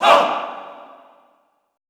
MALE HAH  -R.wav